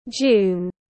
Tháng 6 tiếng anh gọi là june, phiên âm tiếng anh đọc là /dʒuːn/
June /dʒuːn/